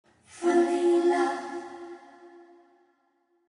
sorry for got to inlude the mp3 vietnames call " nhac be`"